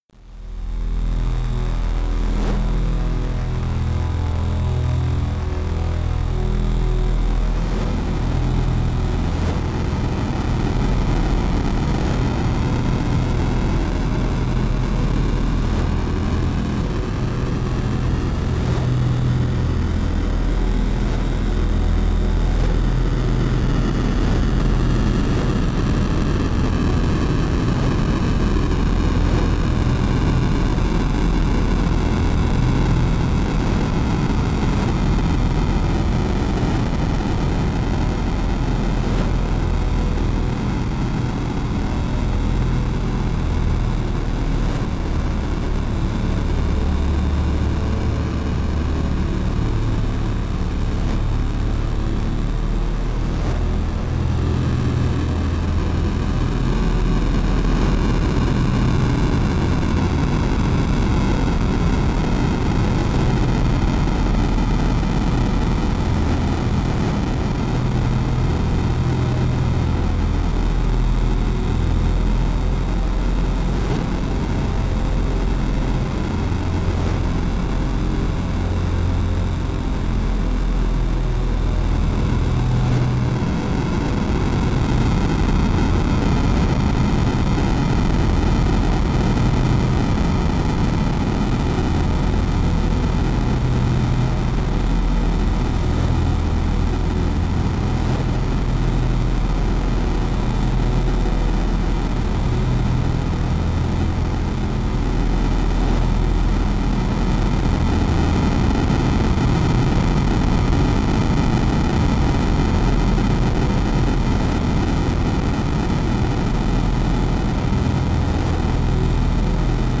Ambient electronic sound